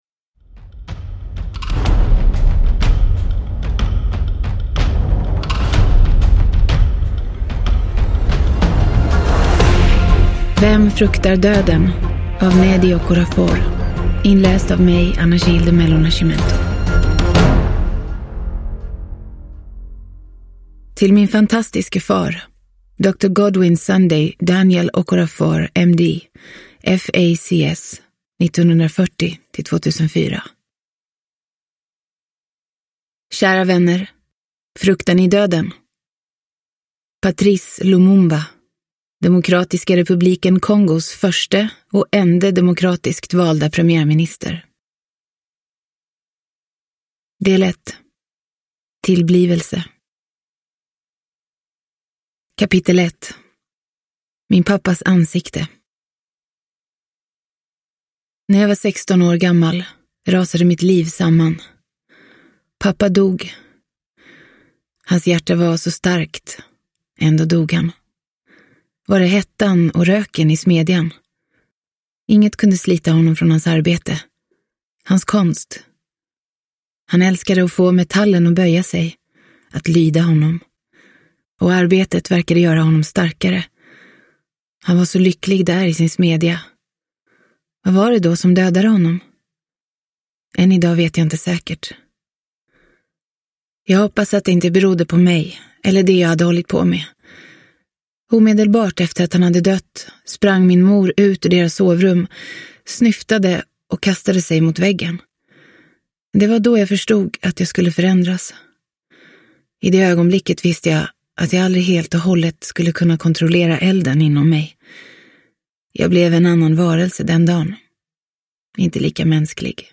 Vem fruktar döden – Ljudbok – Laddas ner